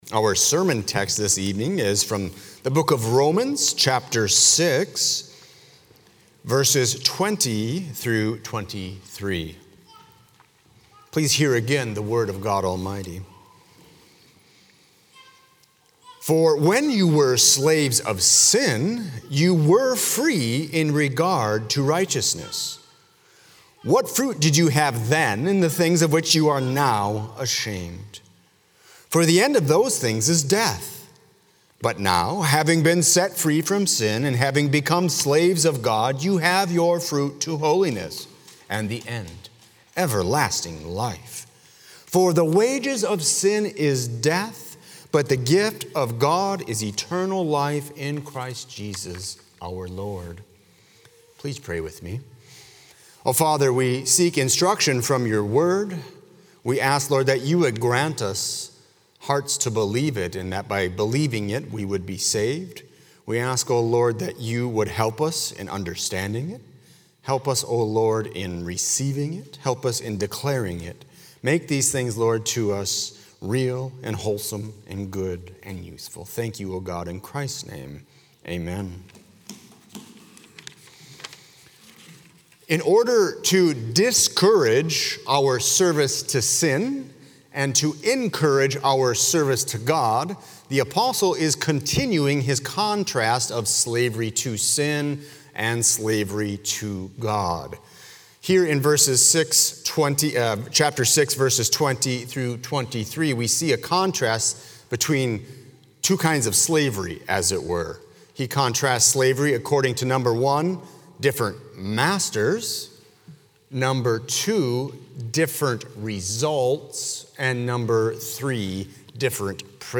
00:00 Download Copy link Sermon Text Believing in Jesus Christ as the eternal Son of God